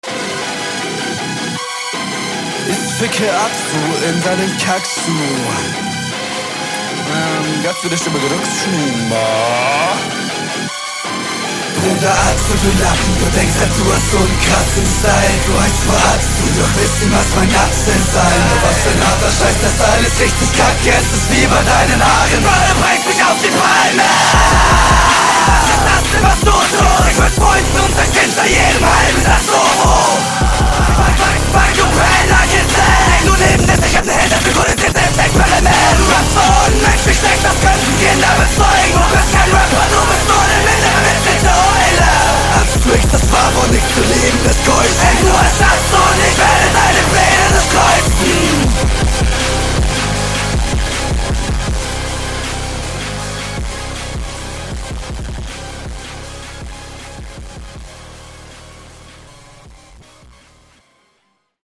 zum teil nicht gut gerappt, aber insaaaaaaaaaaner stimmeinsatz. ideen sind da, aber du schreist da …